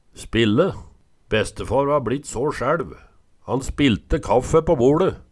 Høyr på uttala Ordklasse: Verb Attende til søk